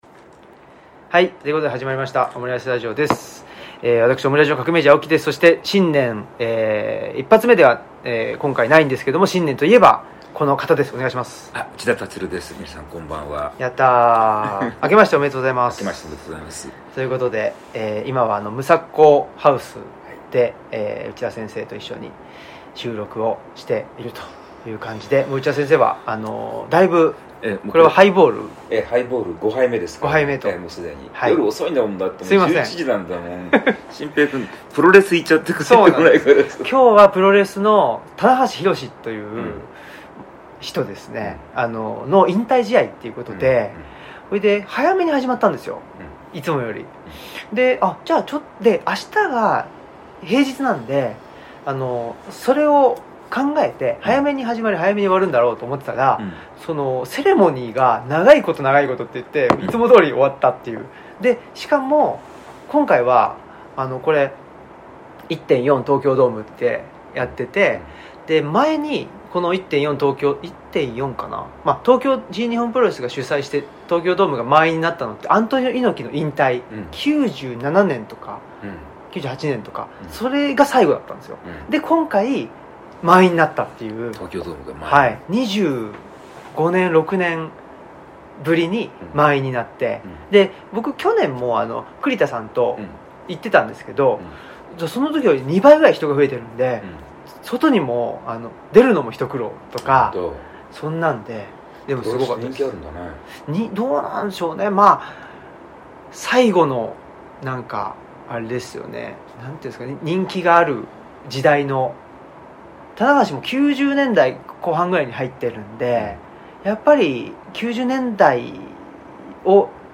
二人の師匠・内田樹先生を迎えたオムラヂ600回記念公開収録の模様を配信。